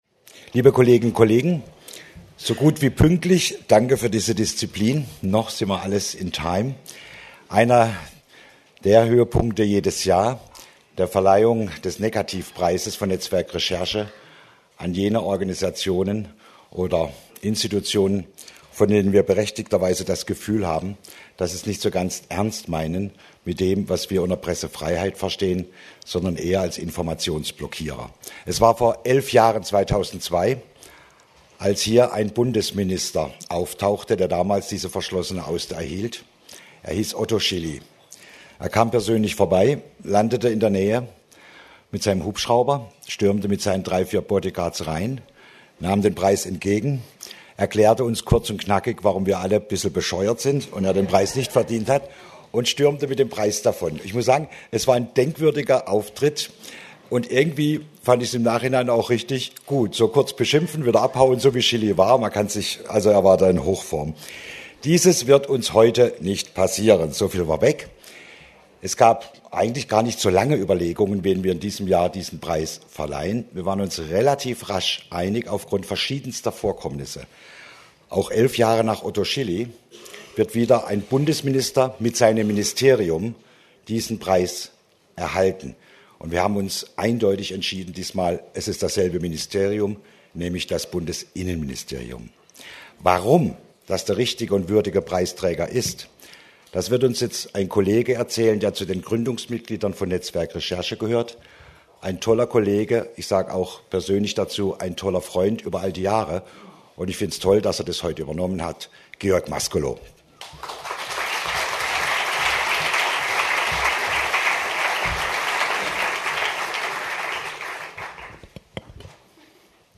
Jahrestreffen netzwerk recherche Wo: Hamburg, NDR-Fernsehzentrum Wann
Georg Mascolo während der Laudatio auf das Bundesinnenministerium